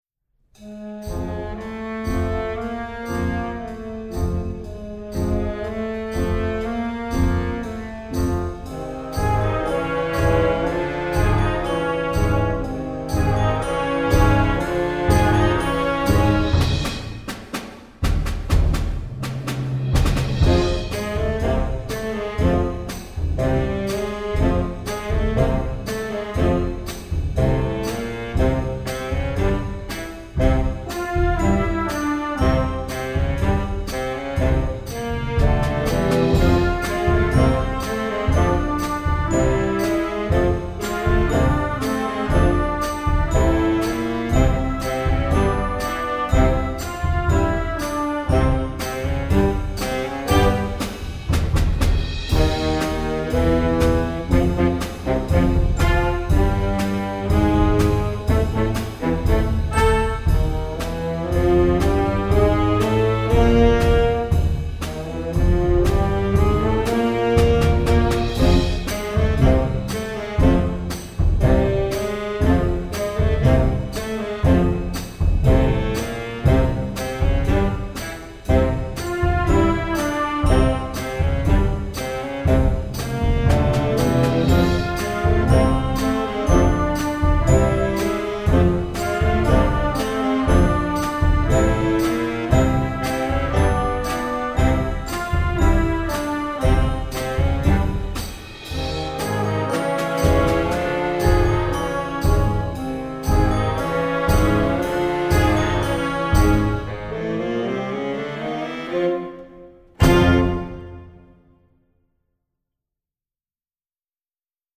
Voicing: Solo / Ensemble w/ Band